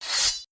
Melee Weapon Draw 2.wav